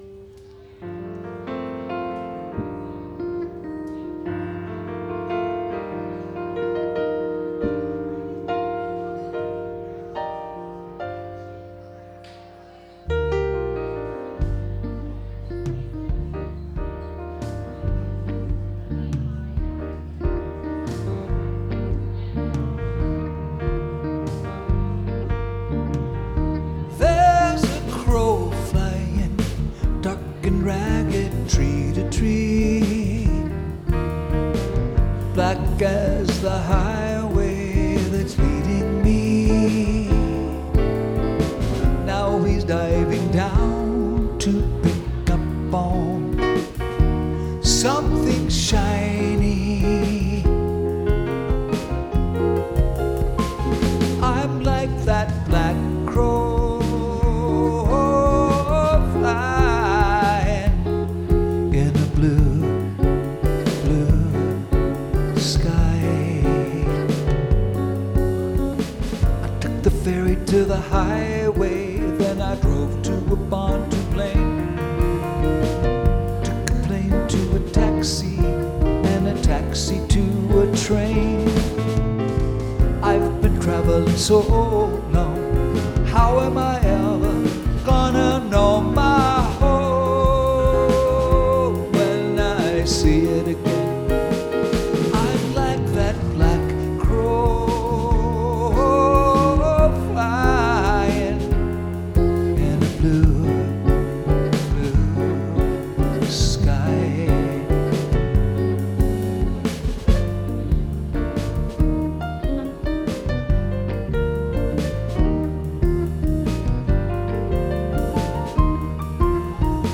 Our version is heavy on the piano.